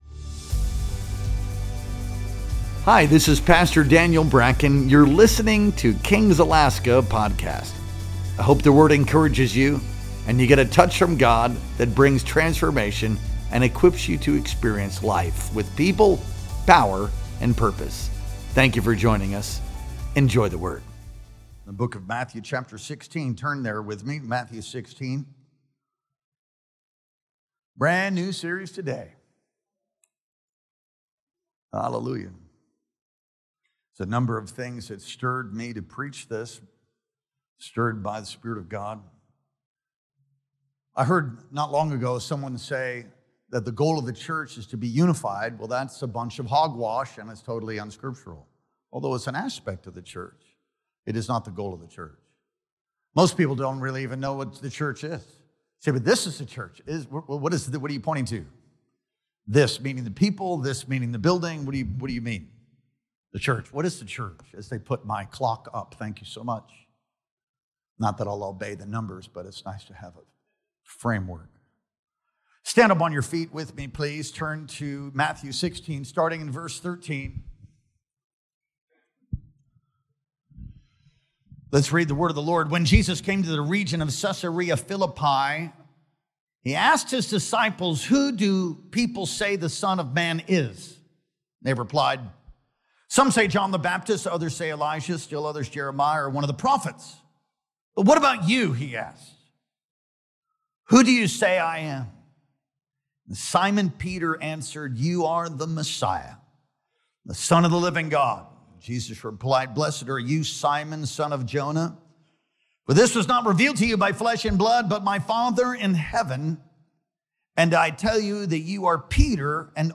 Our Sunday Morning Worship Experience streamed live on August 24th, 2025.